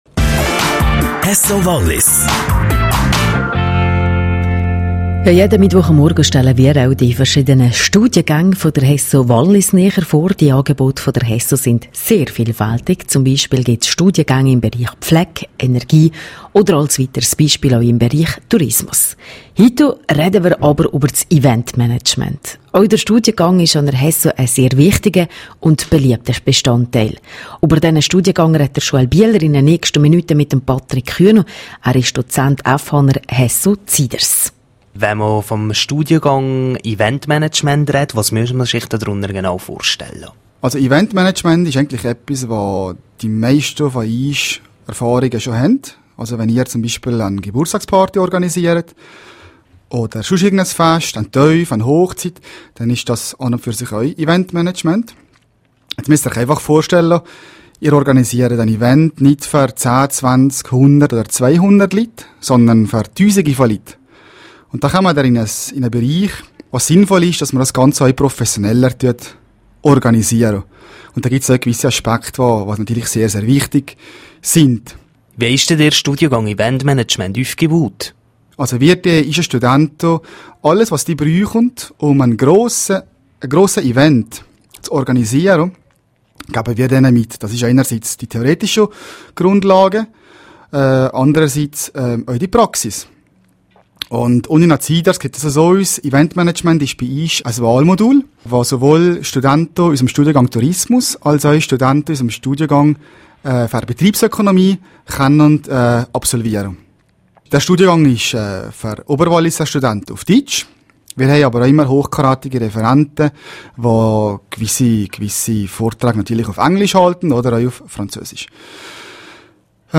im Interview mit rro